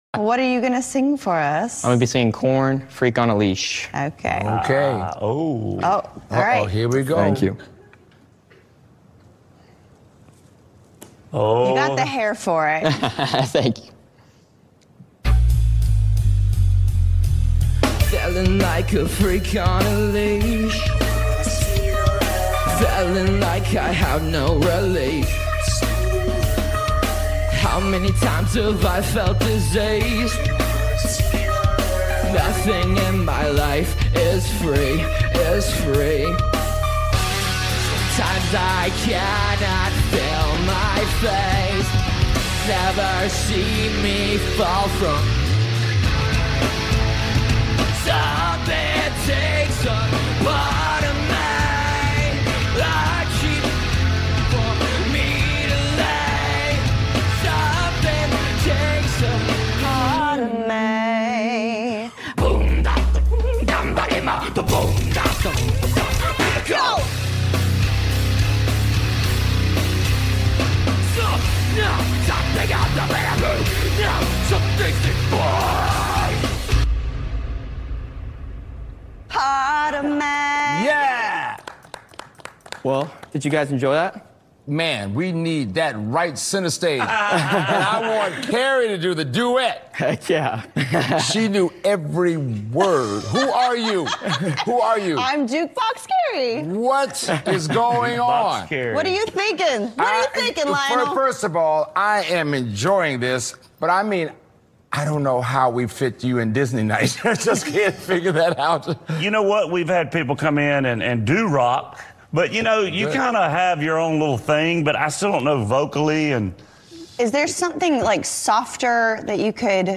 Coming from someone who is not into Heavy Metal Rock.
Full Performance